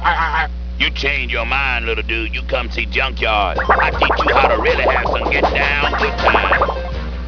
Additionally, terrible sound effects were used, like
Shake it with both hands, so it makes a metal warbling sound.
junkyard.wav